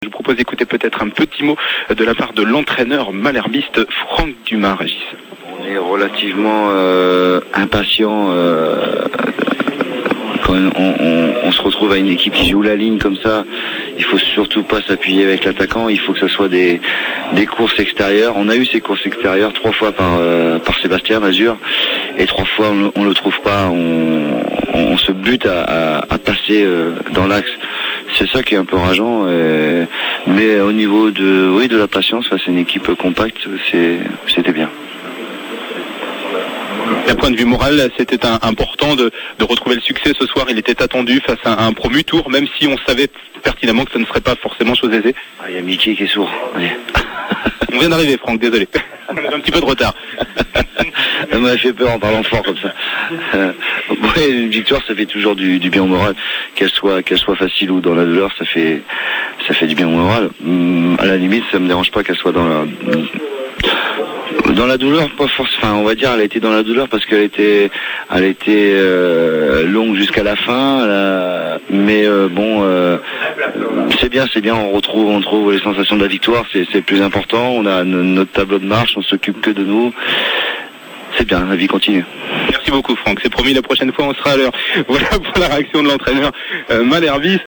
interview complète